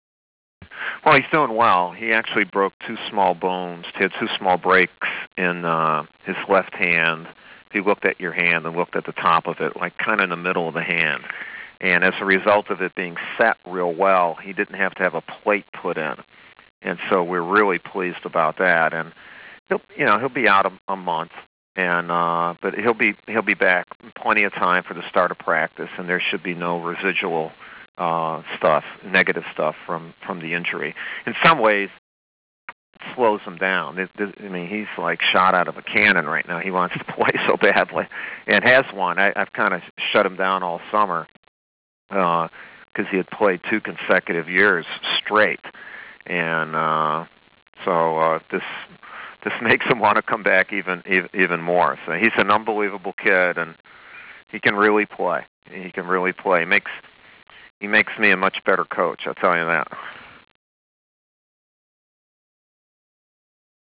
Duke head coach Mike Krzyzewski breaks down Jason Williams' injury status and what it means for Duke and another National Championship.